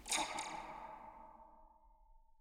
Percussion
zap4_v1.wav